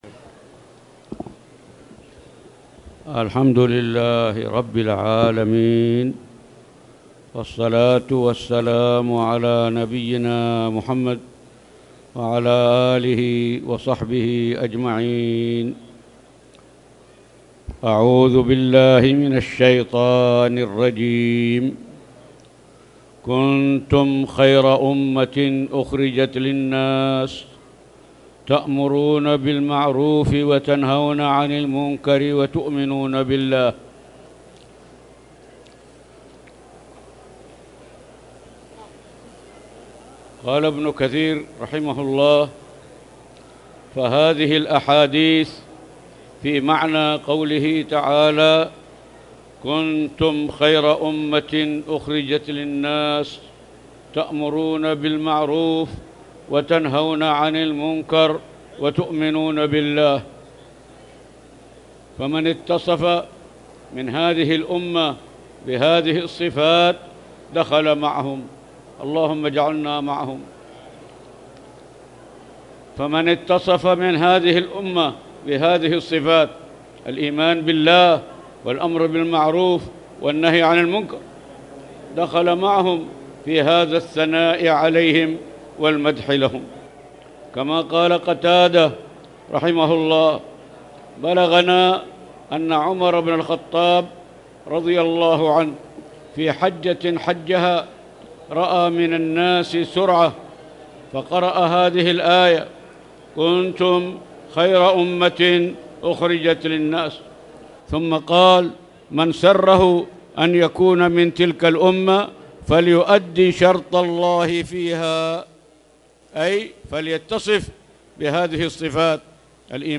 تاريخ النشر ١٨ رجب ١٤٣٨ هـ المكان: المسجد الحرام الشيخ